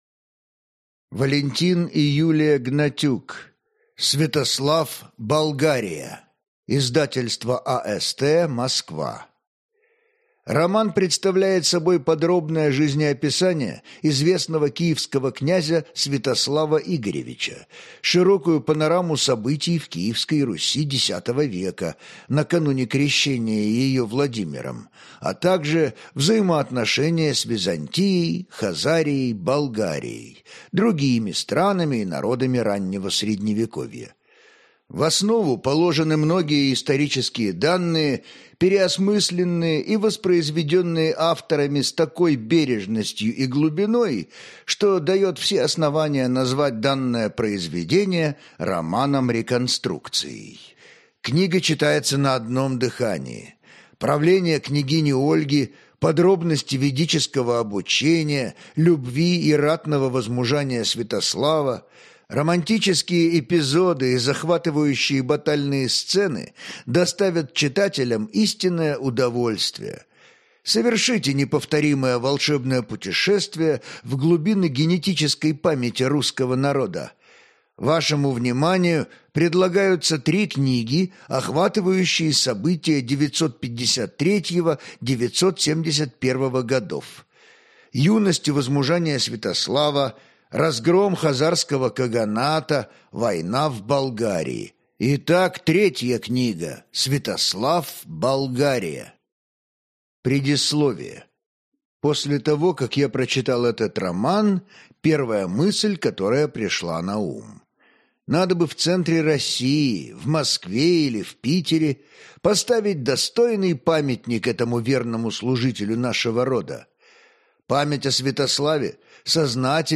Аудиокнига Святослав. Болгария | Библиотека аудиокниг